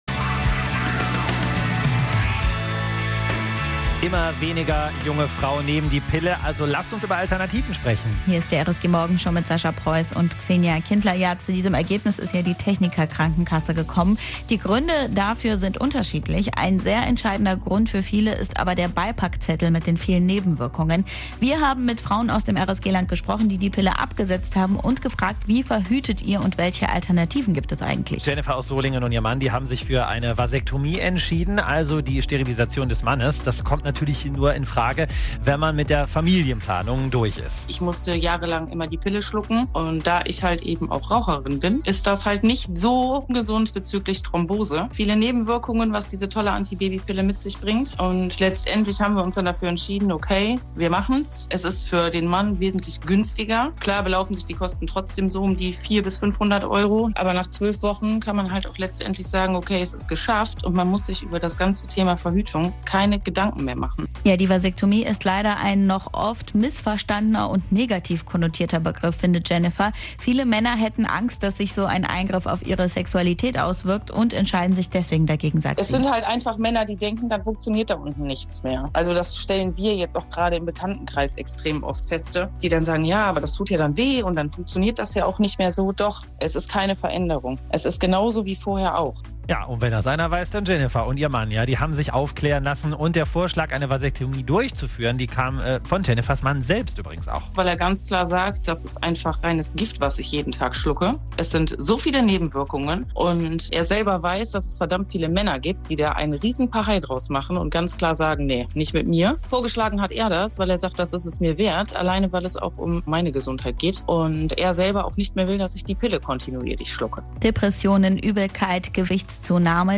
Wir haben mit Frauen aus dem RSG-Land über die Pille und alternative Verhütungsmethoden gesprochen.